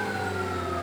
decelerate1.wav